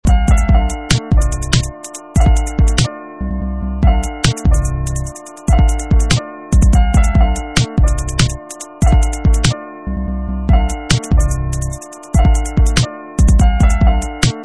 8 Styl: Hip-Hop Rok